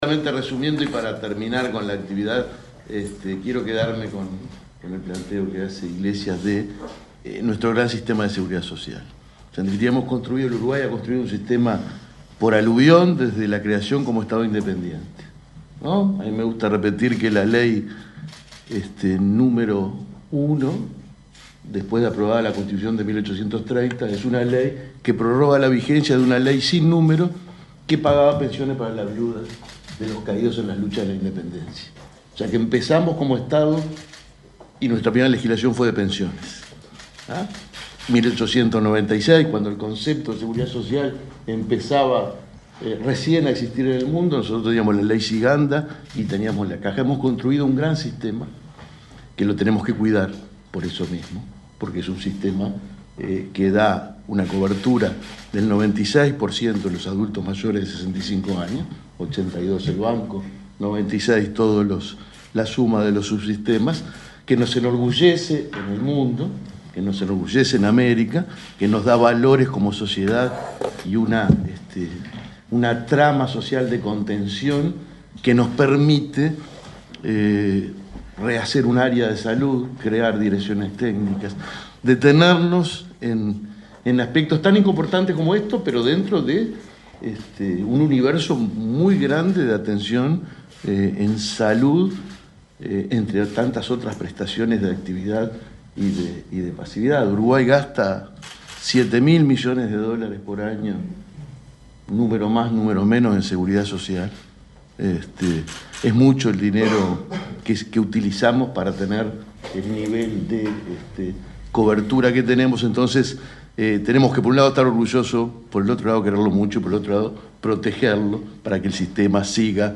Declaraciones del presidente del BPS, Alfredo Cabrera
El presidente del Banco de Previsión Social (BPS), Alfredo Cabrera, participó, este viernes 30 en Montevideo, en el lanzamiento de los talleres